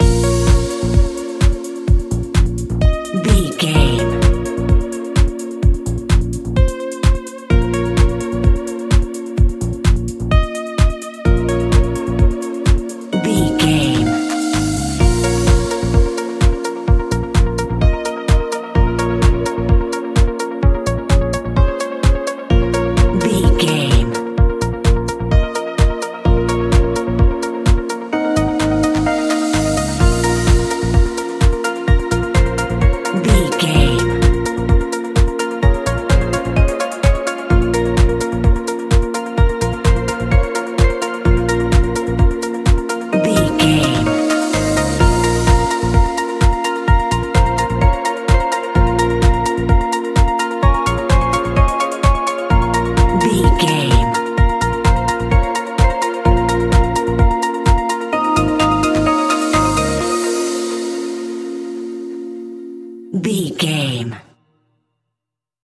Aeolian/Minor
B♭
groovy
uplifting
energetic
drums
drum machine
synthesiser
bass guitar
funky house
upbeat
instrumentals
funky guitar
clavinet